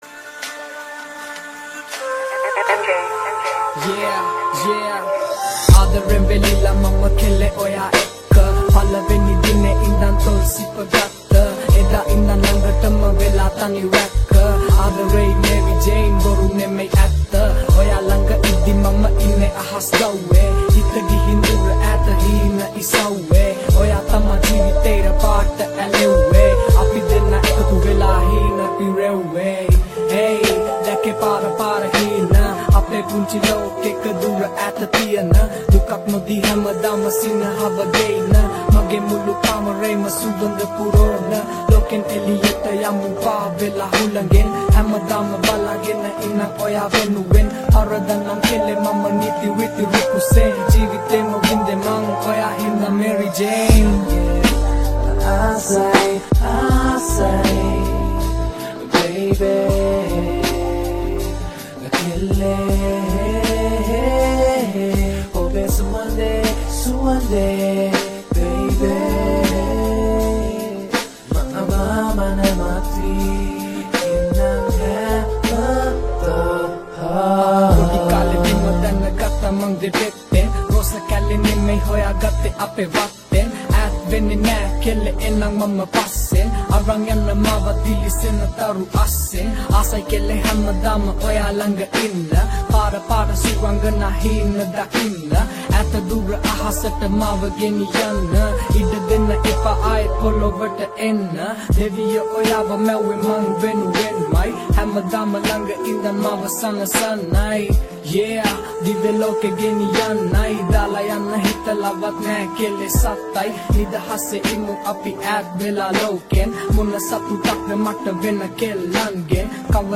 Releted Files Of Sinhala New Rap Mp3 Songs